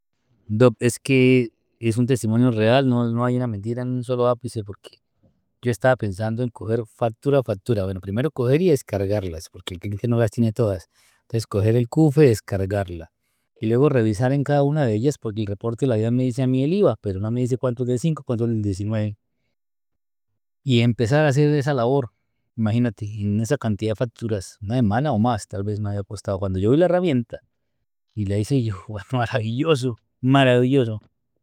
Testimonio - Maravilloso